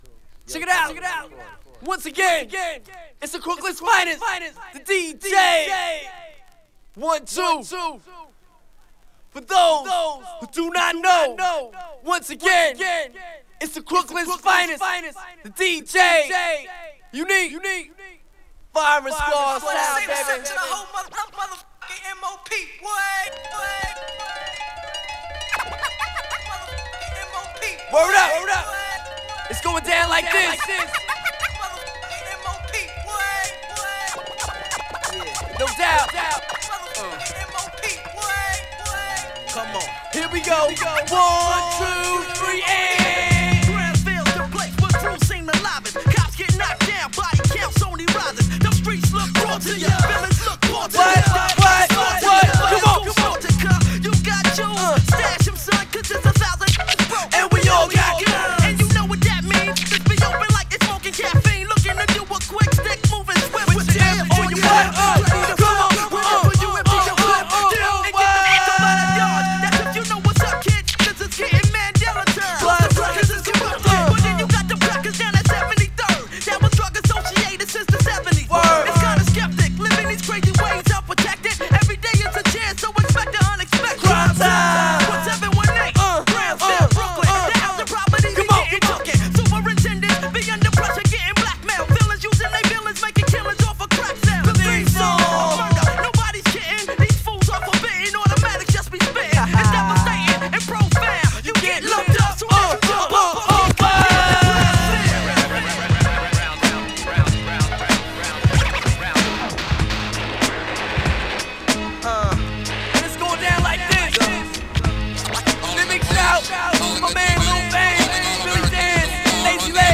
(Acapella Version)